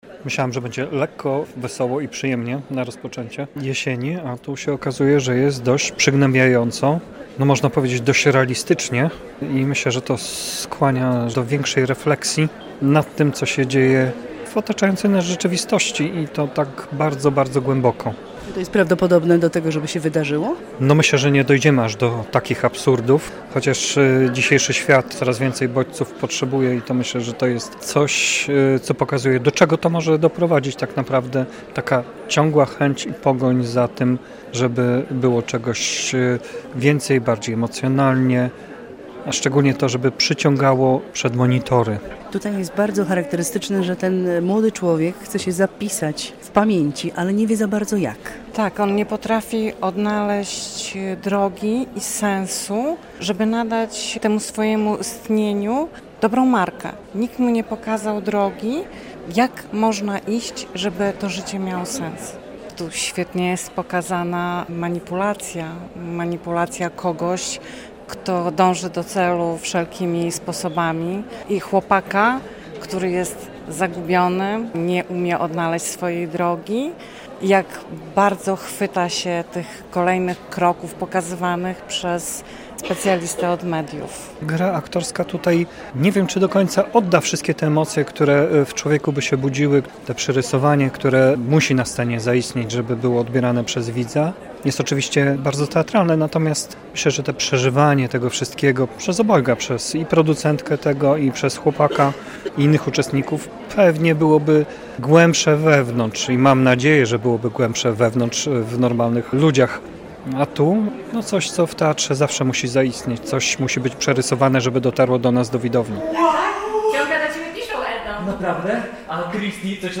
Wrażenia widzów po premierze spektaklu "Trup" w Teatrze Dramatycznym